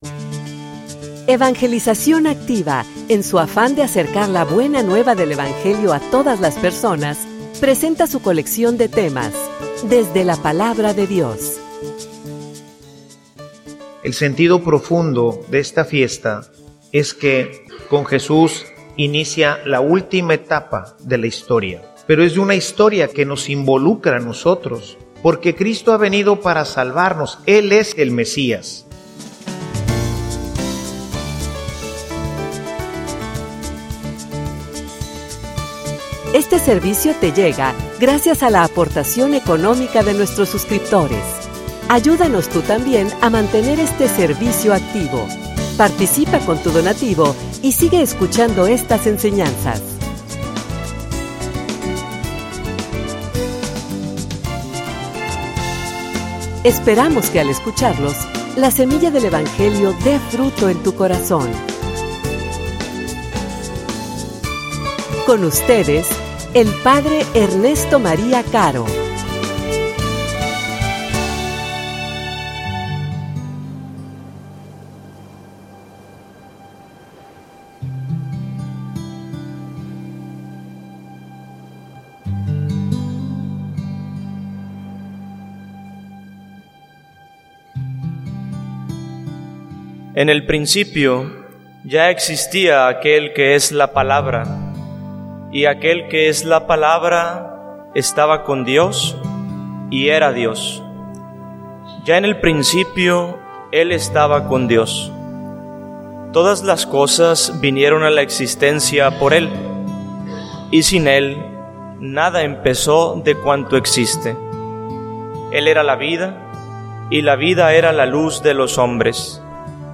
homilia_El_mesias_entre_nosotros.mp3